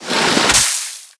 File:Sfx creature snowstalkerbaby down 01.ogg - Subnautica Wiki
Sfx_creature_snowstalkerbaby_down_01.ogg